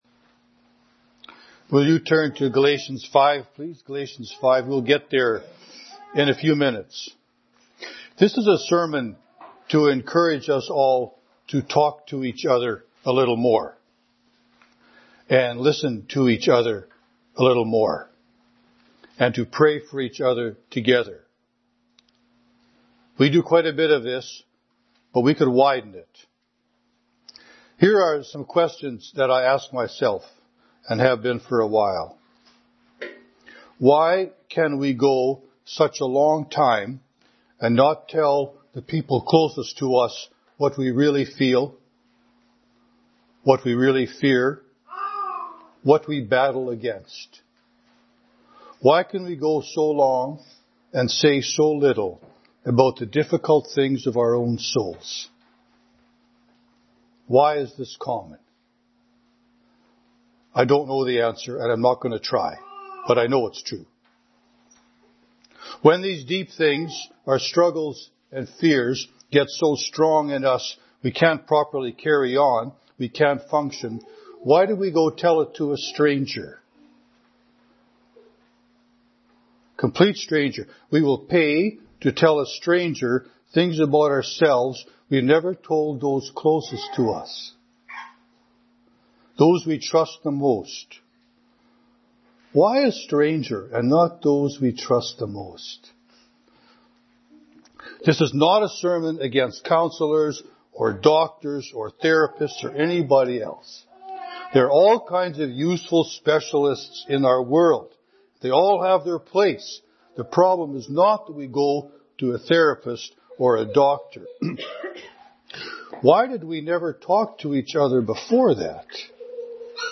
This is a sermon to encourage us all to talk to each other a little more, and listen to each other a little more, and to pray for each other together.